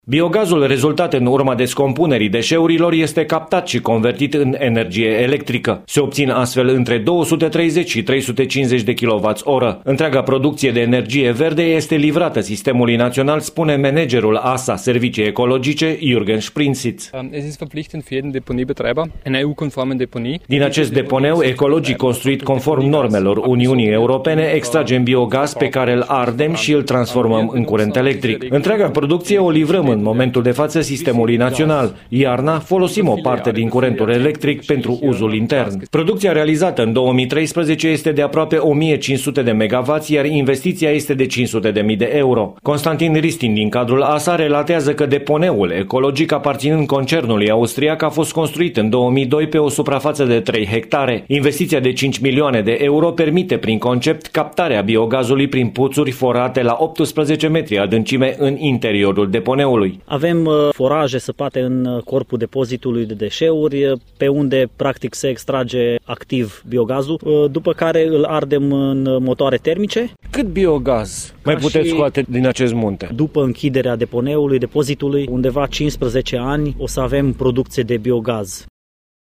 Un reportaj realizat de corespondentul nostru